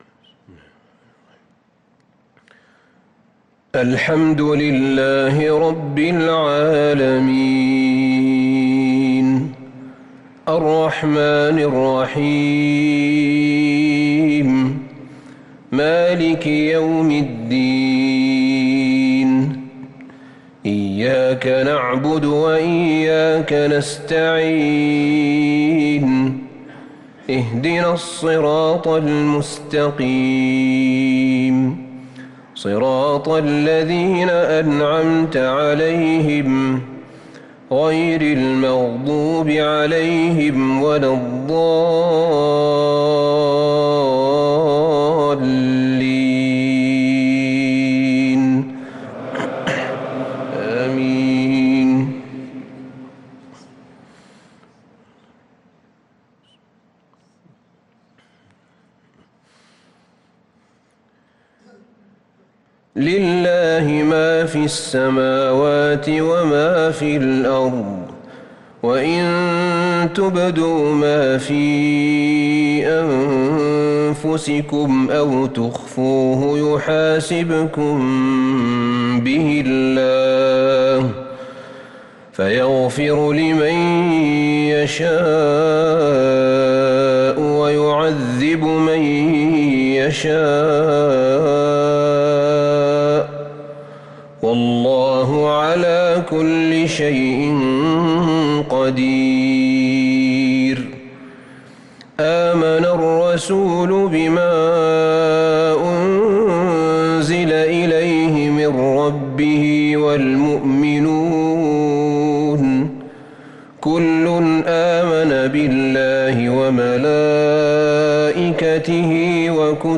صلاة العشاء للقارئ أحمد بن طالب حميد 20 ذو القعدة 1443 هـ